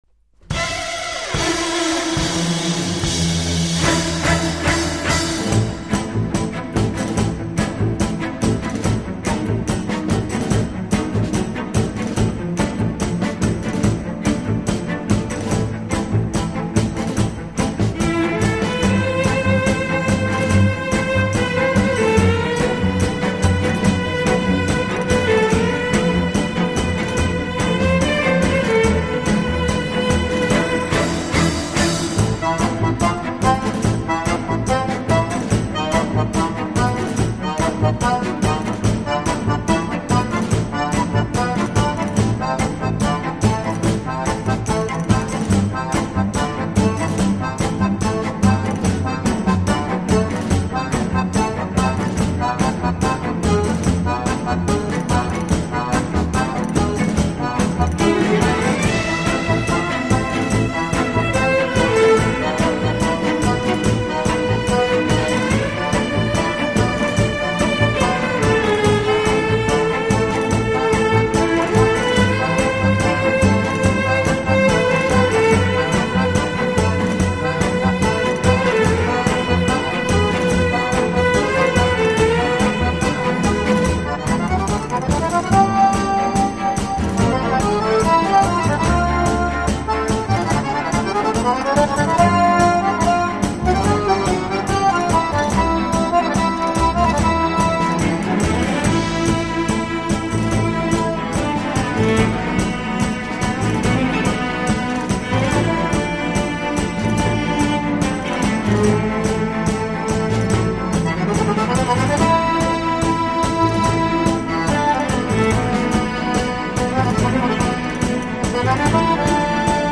Concertina(BANDONEON)_05
六角形手风琴
探戈革命